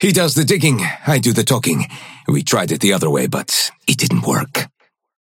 Krill voice line - He does the digging, I do the talking.